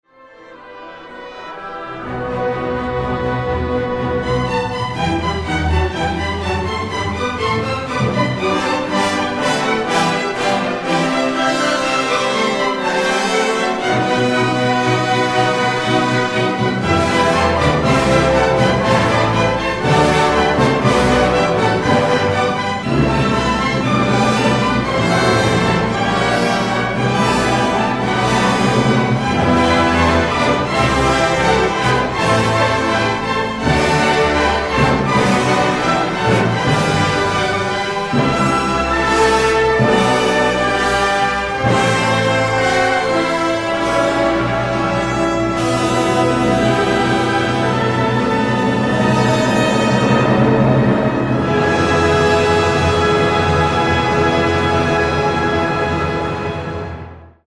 Fascinado por la maestría contrapuntística de la Chacona en mi menor de Buxtehude, obra del periodo barroco compuesta originalmente para órgano, Carlos Chávez la transcribió en 1937 en dos versiones, una para pequeña orquesta y la otra para orquesta sinfónica, que es la que suele interpretarse en las salas de conciertos.